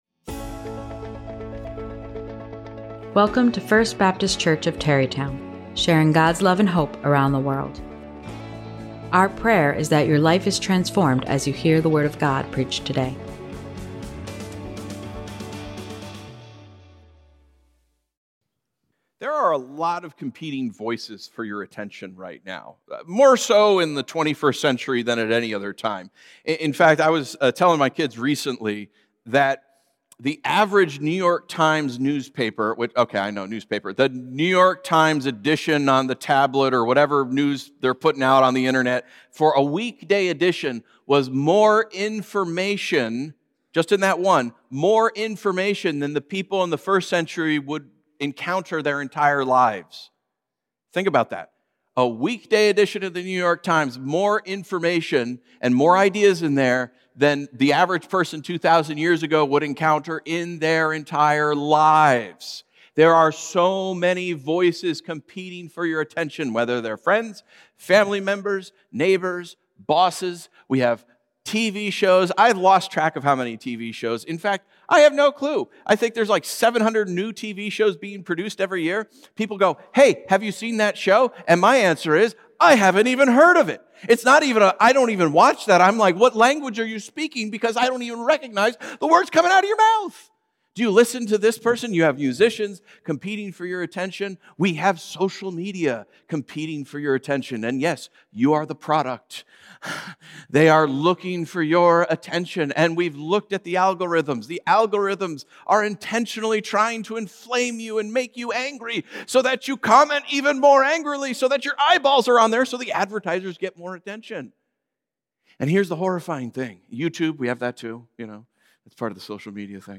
Sermons from First Baptist Church of Tarrytown, NY in Westchester County FBC Tarrytown